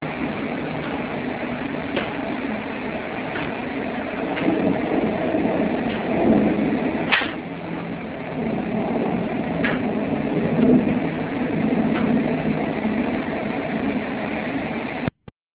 VIDEO CLIP OF DRUM CALL